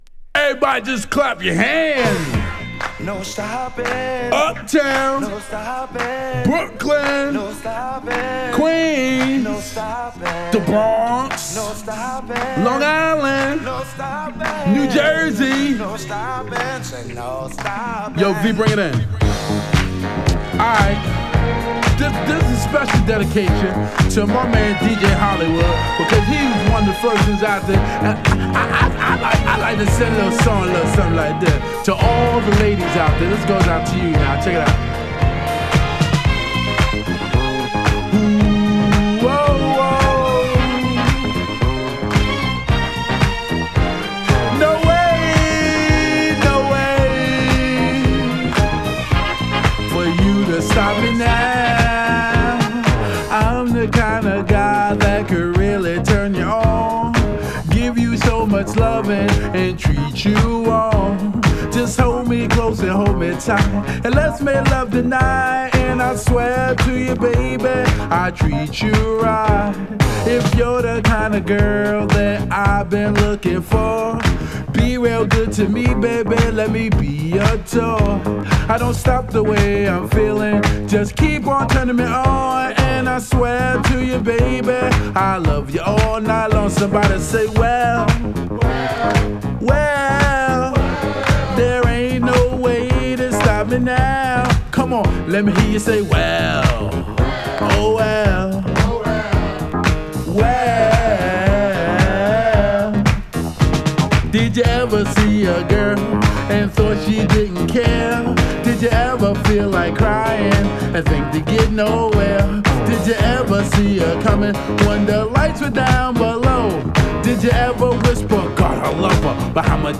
超個性派ラップ & ヴォーカルが炸裂！